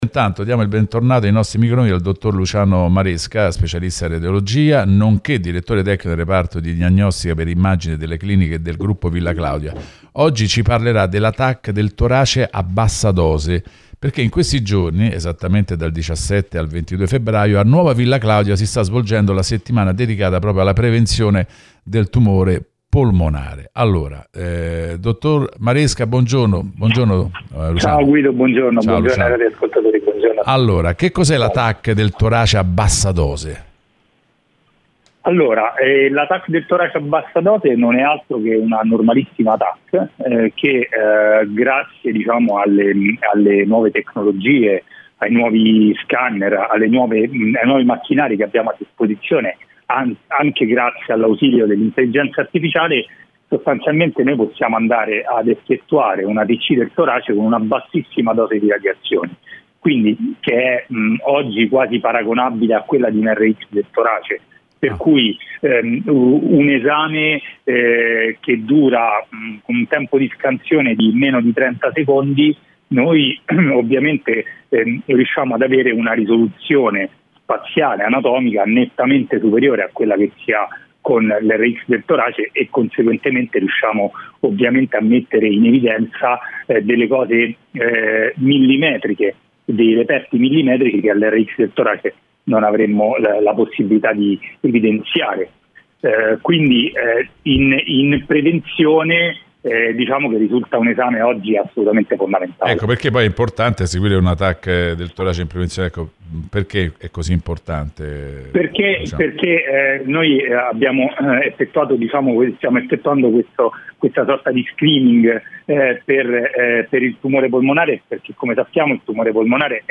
Approfondimenti: Intervista al dott.